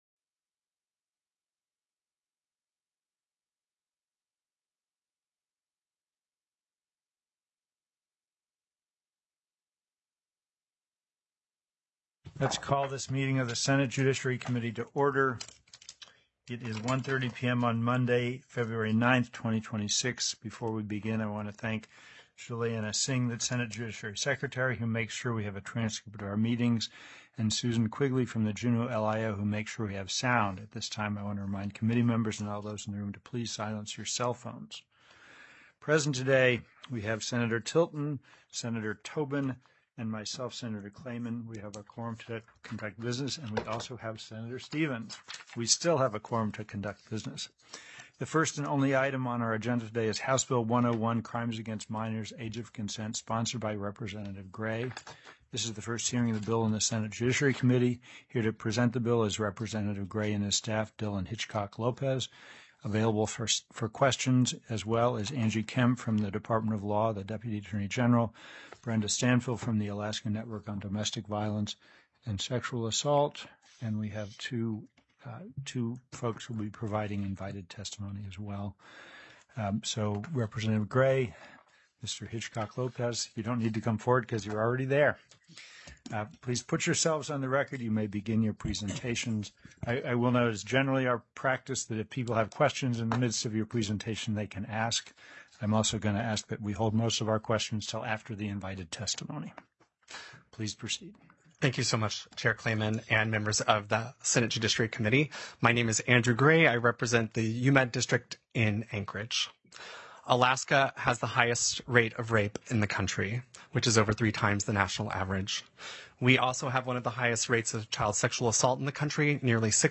The audio recordings are captured by our records offices as the official record of the meeting and will have more accurate timestamps.
HB 101 CRIMES AGAINST MINORS; AGE CONSENT TO 18 TELECONFERENCED